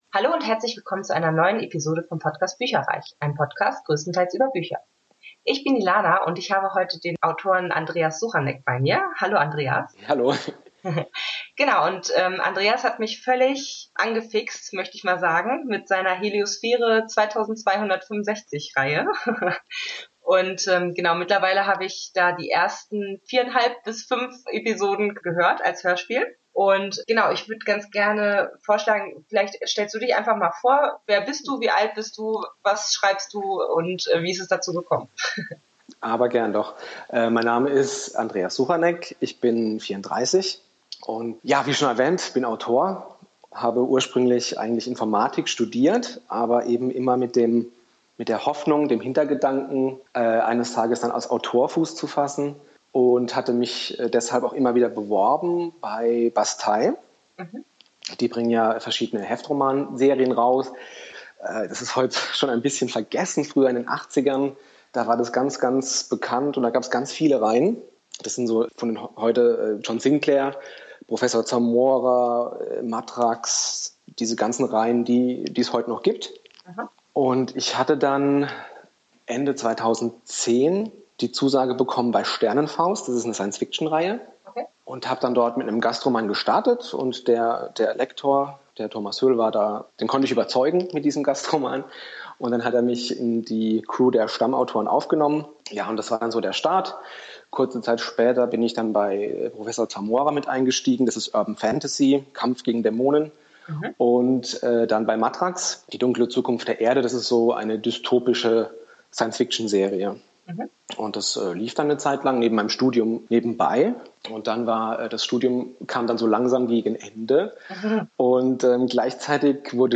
Willkommen bei bücherreich, einem Podcast größtenteils über Bücher!
Entschuldigt bitte, dass die Tonqualität leider nicht auf dem üblichen Niveau ist, da das Interview via Skype geführt und aufgenommen wurde.